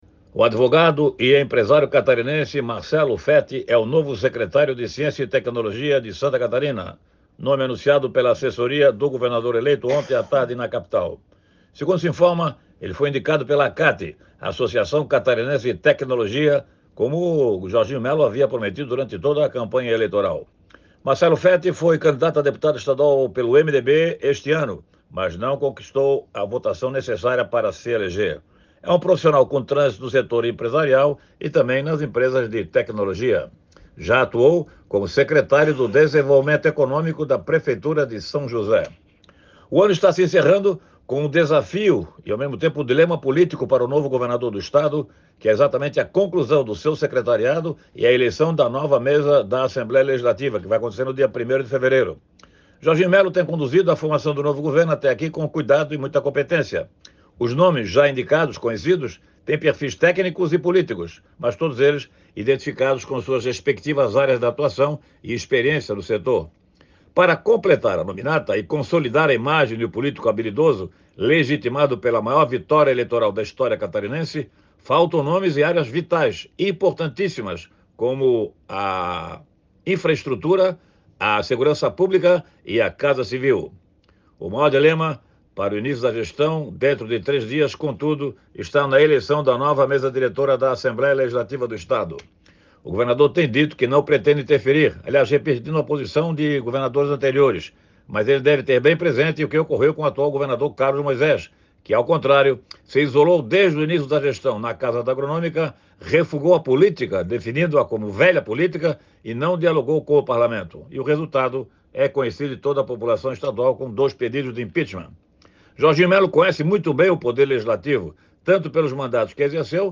Confira o comentário: